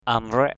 /a-mrɛʔ/ (d.) ớt = piment (Capsicum frutescens). chili. amraik kalu a=mK kl~% tiêu = poivre. pepper. amraik catai langik a=mK c=t lz{K ớt hiểm; ớt chỉ thiên = Fasciculatum, Bail....